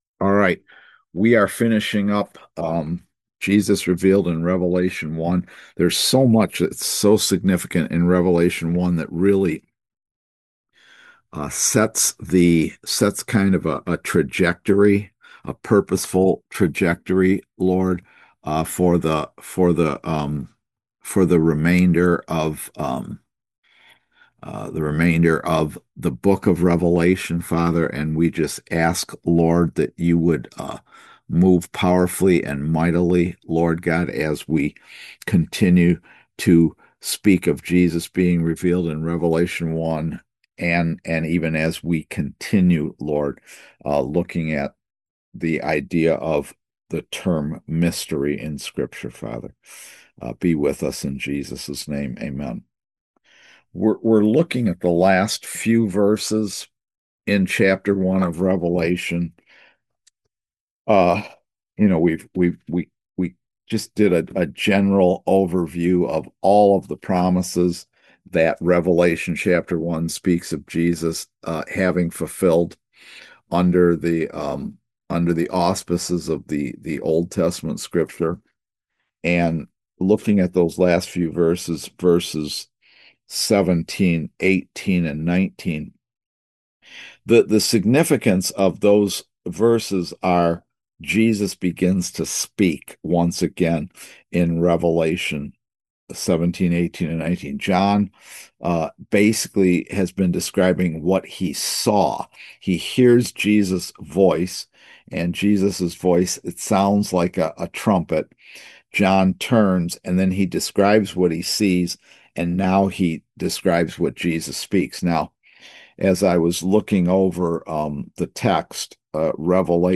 Series: Eschatology in Daniel and Revelation Service Type: Kingdom Education Class